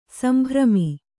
♪ sambhrami